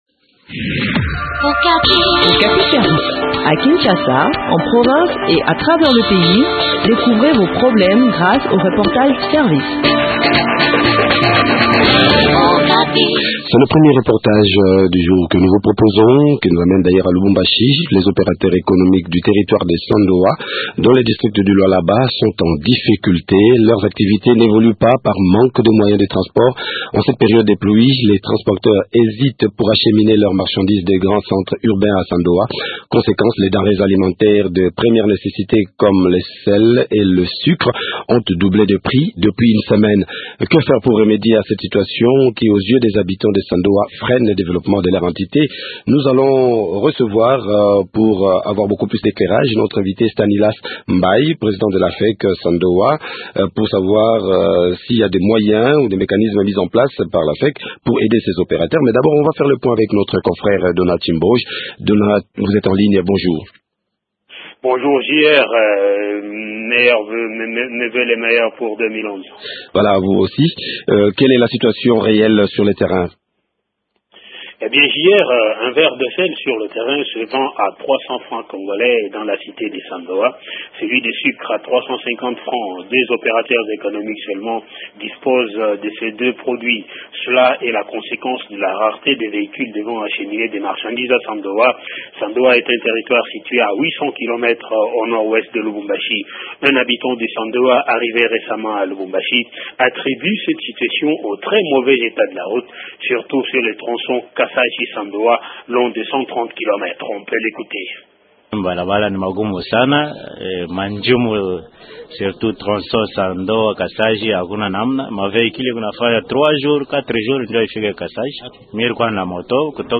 Eléments de réponse dans cet entretien